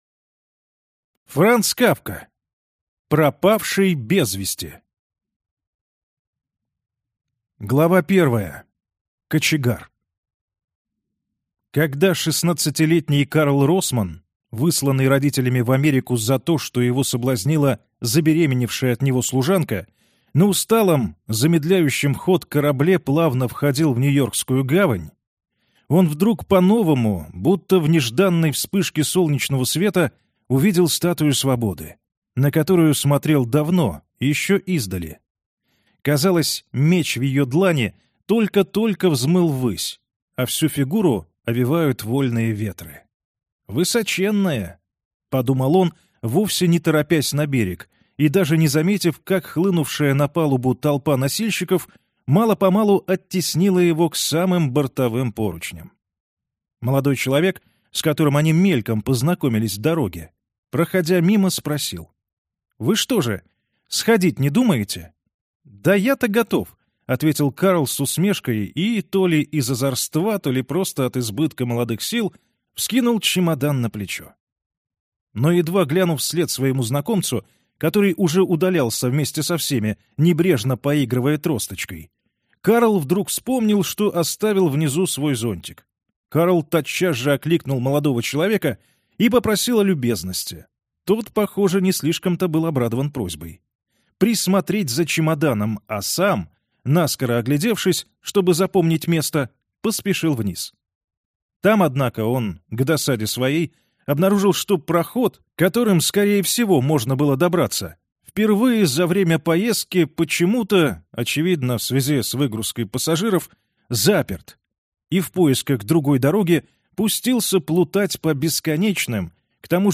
Аудиокнига Пропавший без вести | Библиотека аудиокниг